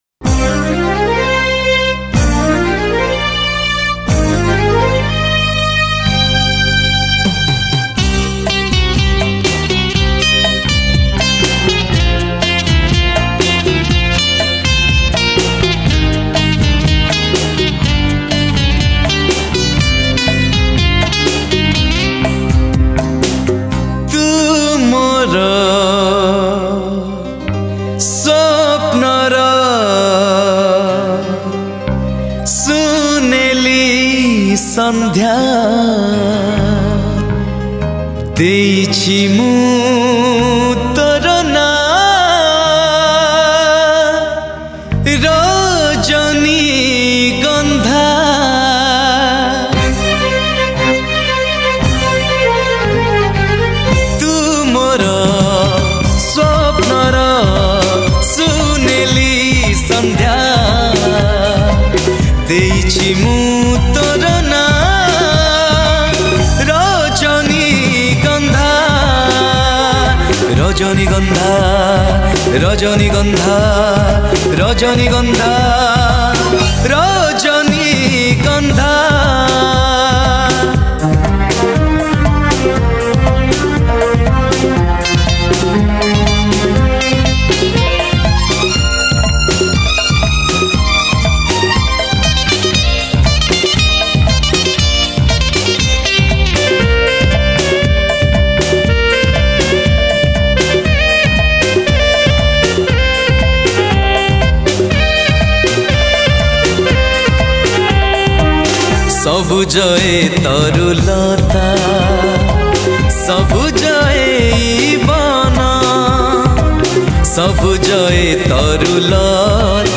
Old Odia Film Song